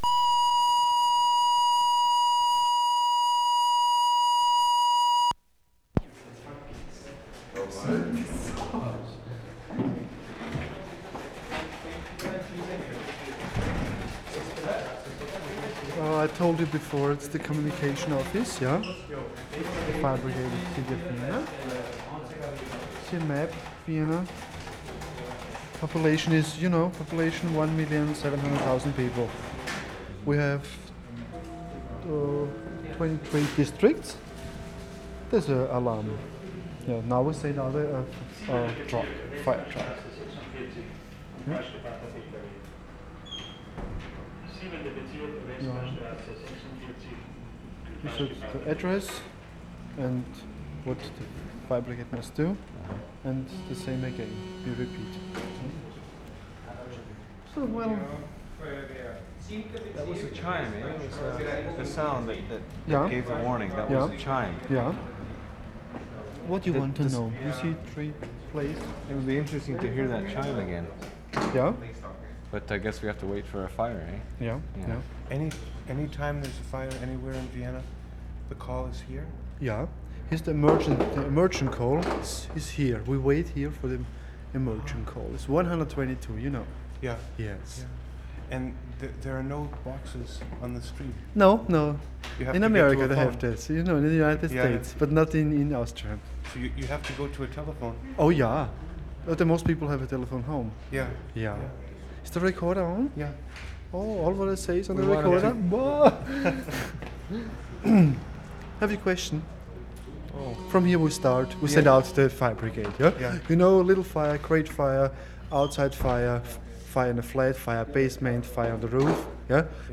Vienna, Austria March 21/75
FIREHALL COMMUNICATIONS ROOM, conversation with guide
mark * chime signal indicating alarm (sounds very much like an old clock, not very alarming). [4:53 & 5:45]